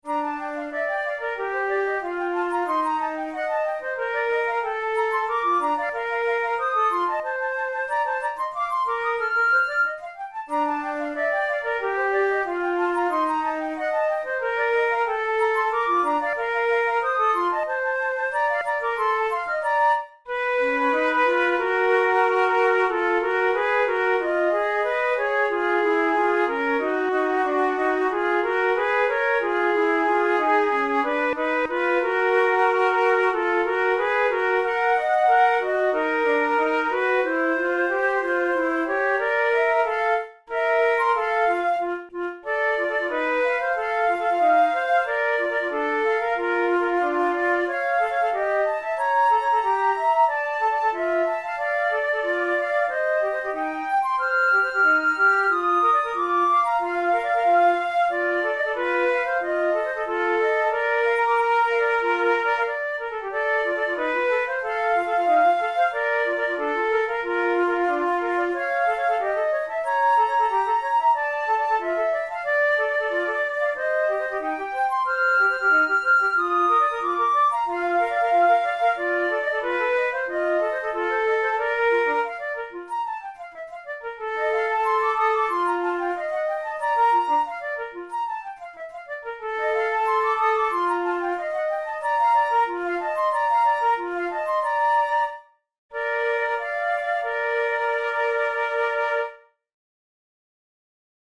from Forty Progressive Duets for Two Flutes
After the exposition of the main theme, which opposes a sixteenth note run to a more lyrical melody, a short slow section in the key of G-flat major (beware the accidentals!) is introduced. The two flutes are treated almost equally in the very first part of the duet, but the second flute is subsequently relegated to an accompaniment role based on a characteristic rhythmic pattern.
Categories: Romantic Written for Flute Difficulty: intermediate